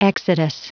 Prononciation du mot exodus en anglais (fichier audio)
Prononciation du mot : exodus